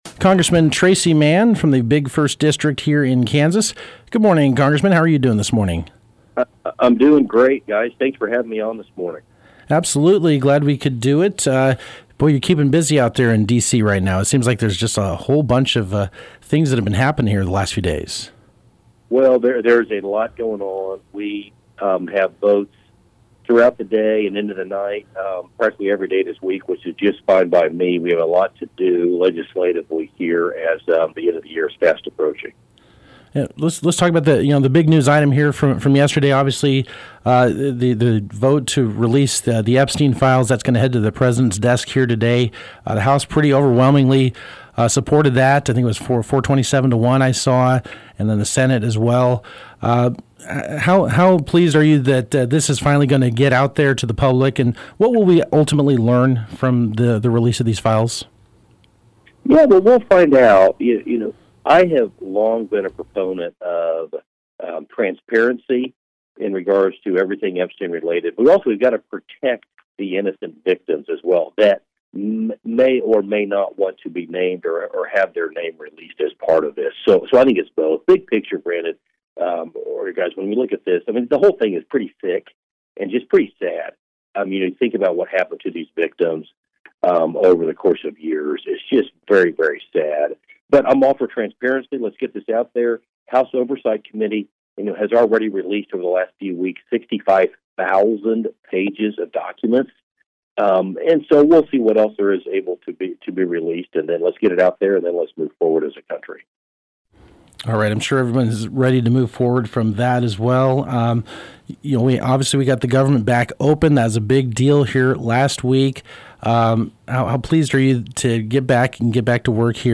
Rep. Tracey Mann (KS-01) called in live Wednesday morning to KMAN’s Morning News to discuss the House vote to release the Epstein files. The Kansas Republican also touched on priorities following the government shutdown, including health care, trade and addressing the rising cost of beef.